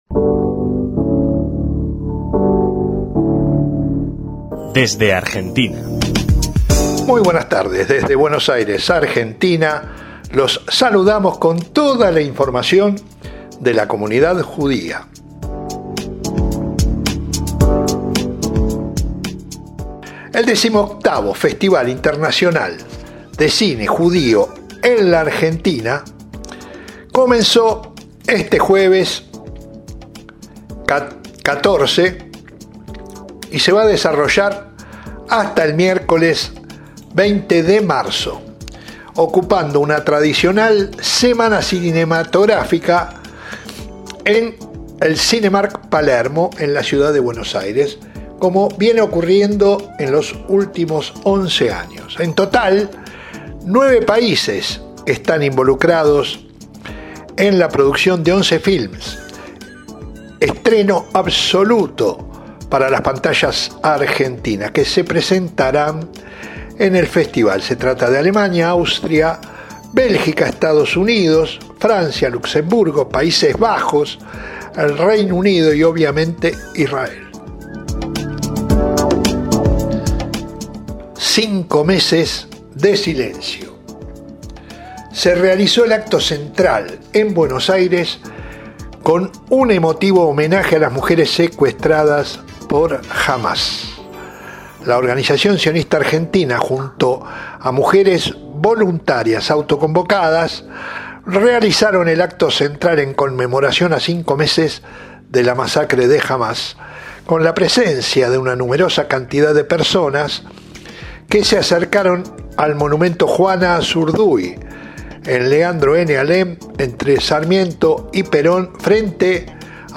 Nuevo informe bisemanal desde Argentina y desde la redacción de Vis a Vis